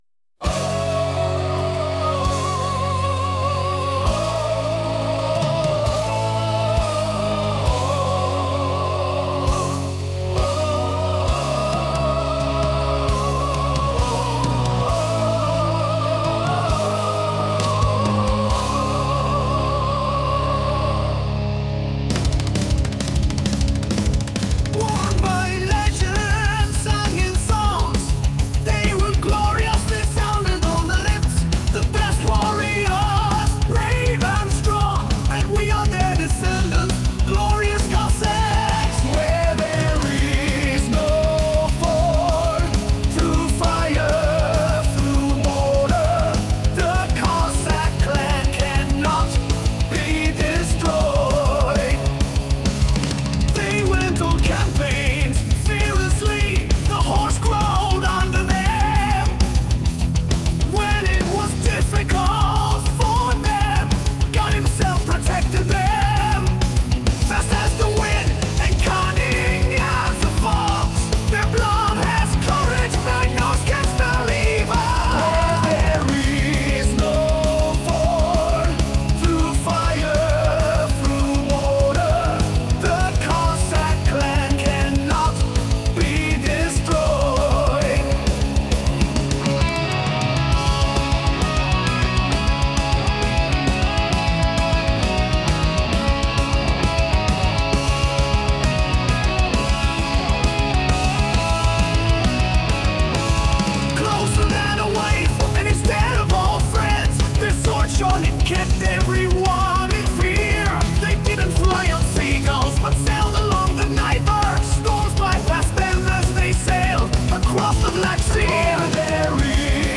The song is the English version. The text is my own, the music and vocals are generated by artificial intelligence AI.